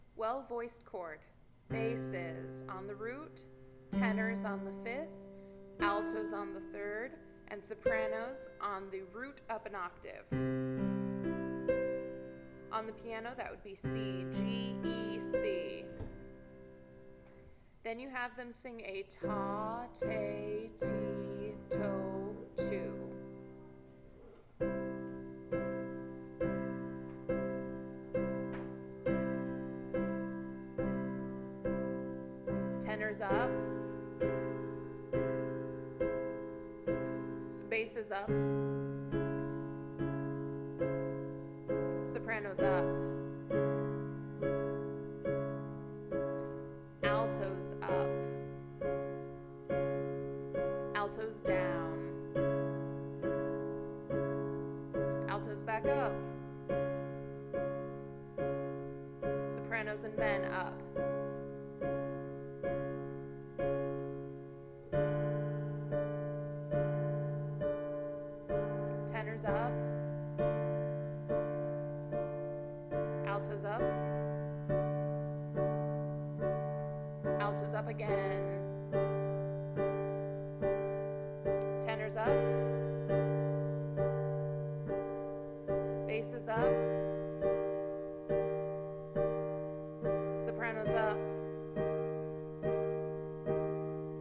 In an SATB choir, I put basses on the root note (I start them in the key of C) tenors on the fifth, altos on the third, and sopranos on the upper root.
Once everyone is singing their note, we repeat a “Ta Te Ti To Tu” pattern.
Then have one voice part at a time move up a half step.
Ideally this entire exercise should be done a capella.
Half-step partwise chord exercise
Half-step-partwise-chord-exercise.mp3